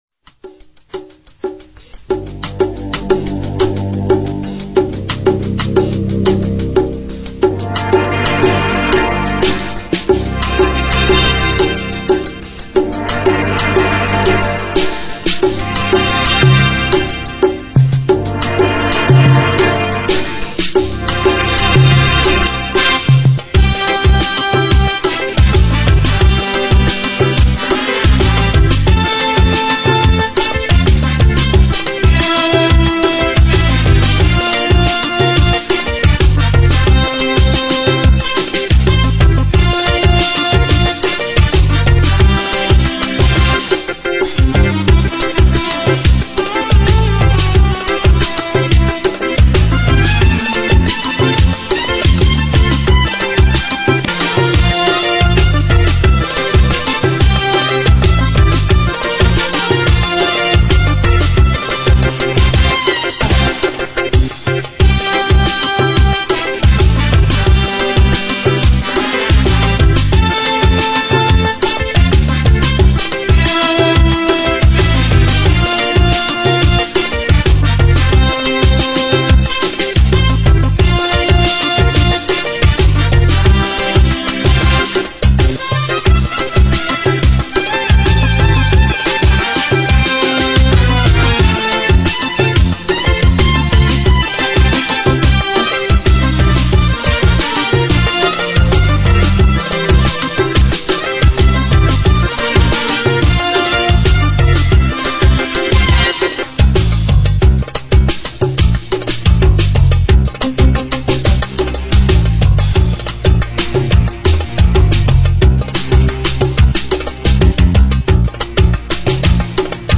musique instrumentale